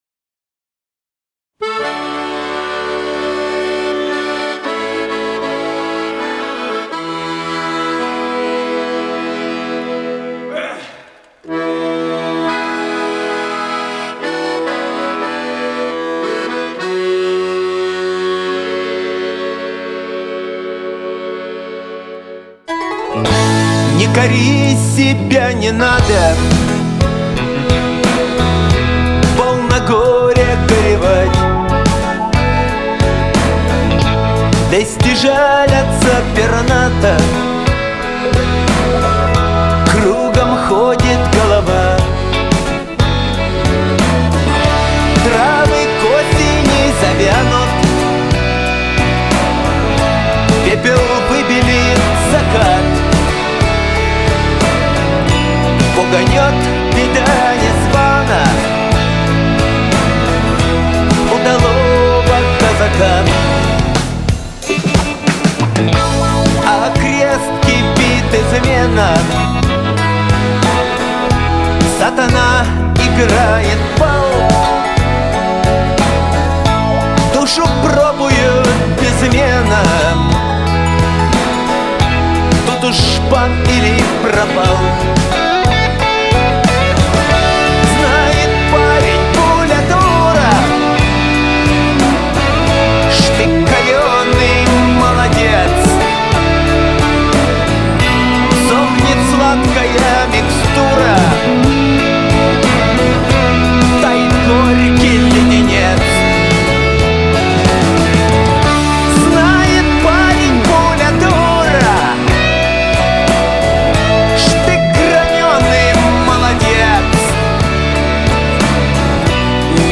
Русский рок Фолк рок